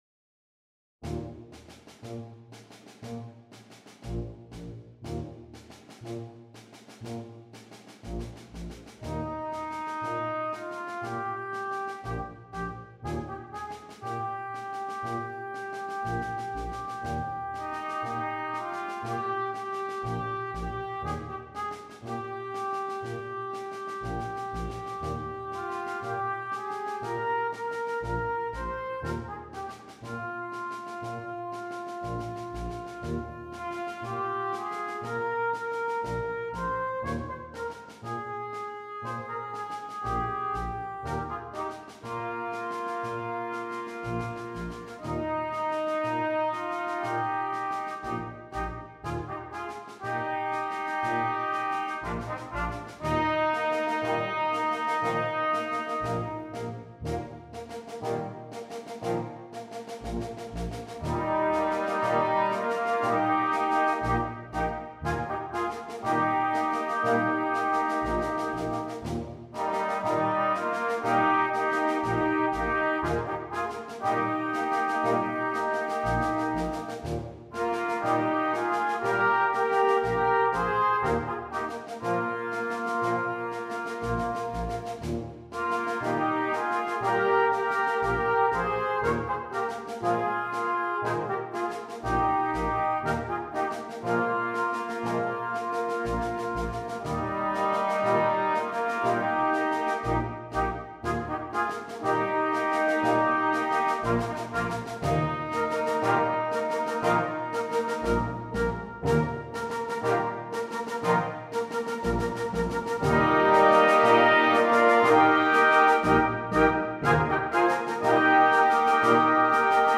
2. Brass Band
Full Band
without solo instrument
Christmas Music